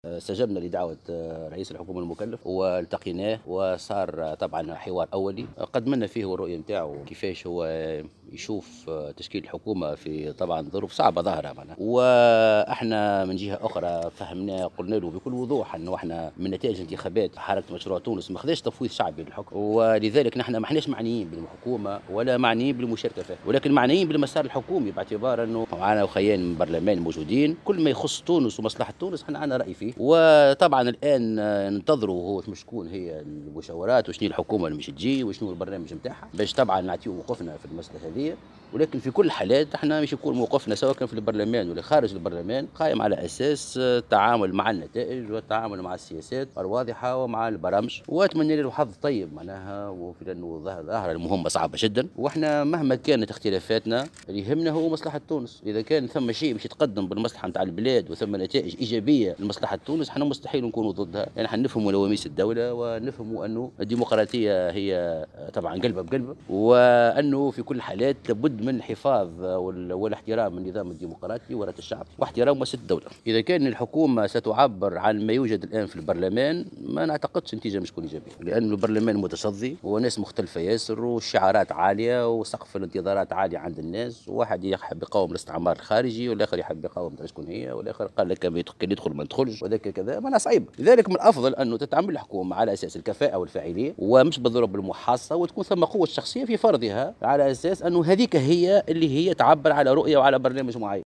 قال أمين عام حركة مشروع تونس محسن مرزوق عقب لقائه رئيس الحكومة المكلف الحبيب الجملي اليوم الخميس إن حزبه غير معني بالحكومة ولا بالمشاركة فيها نظرا إلى أن حركة مشروع تونس لم تتحصل على تمثيلية واسعة في البرلمان.